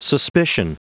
Prononciation du mot suspicion en anglais (fichier audio)
Prononciation du mot : suspicion